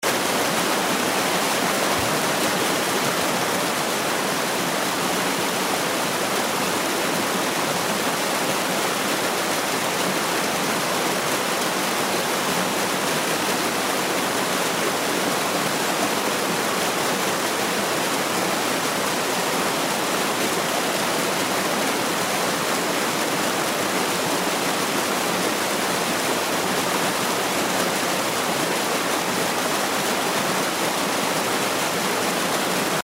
río-caudaloso.mp3